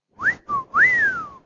படிமம்:Human whistling.ogg - தமிழர்விக்கி
Human_whistling.ogg.mp3